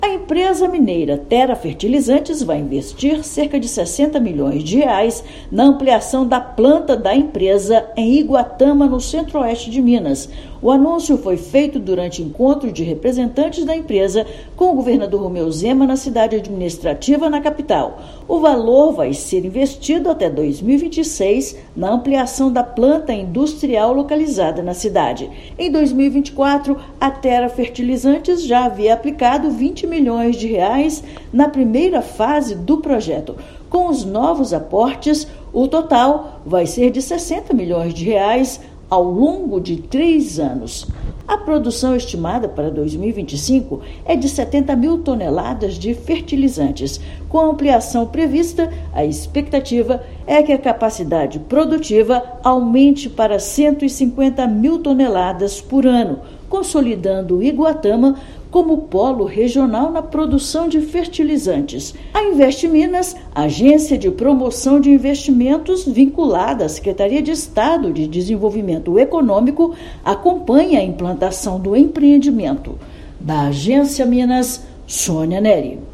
Unidade industrial da Tera Fertilizantes está em construção e vai gerar 40 empregos diretos quando estiver em pleno funcionamento, em 2026. Ouça matéria de rádio.